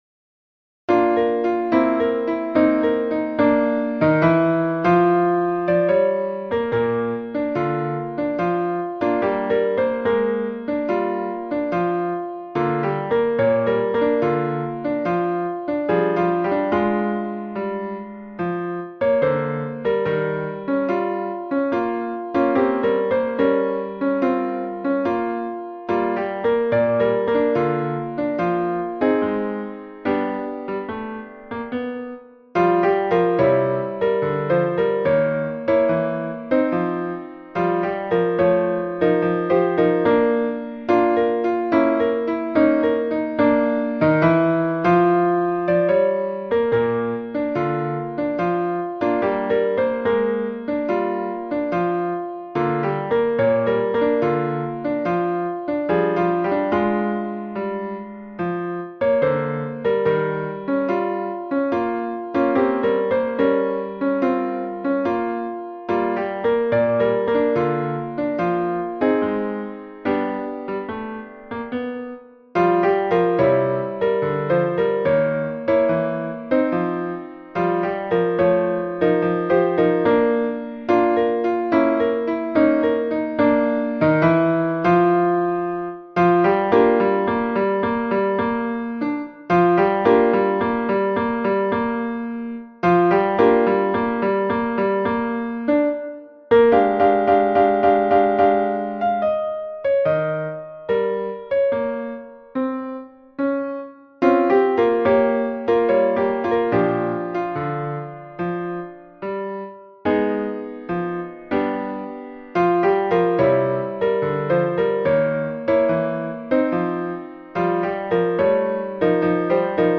- Œuvre pour chœur à 4 voix mixtes a capella (SATB) ; Paroles et musique : Henri Salvador et Boris Vian
MP3 version piano
Tutti